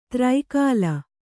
♪ traikāla